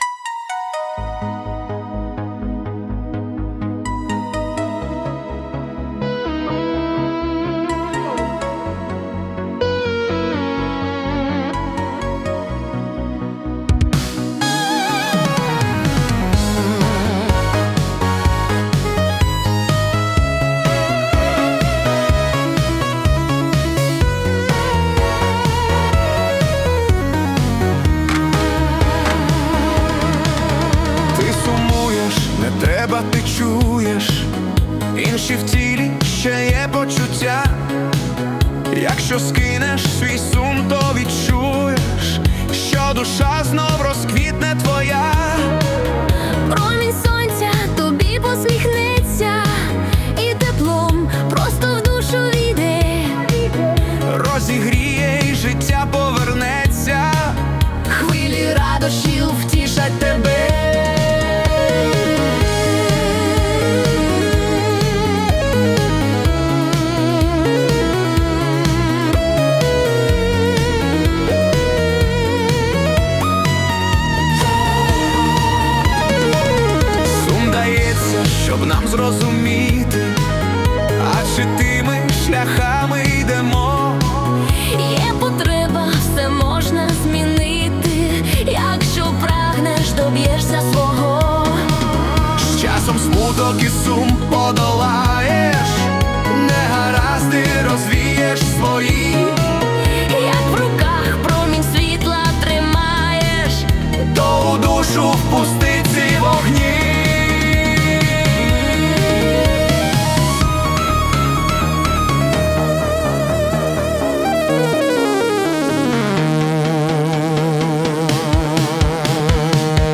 Italo Disco / Duet
це теплий мелодійний дует у стилі Italo Disco.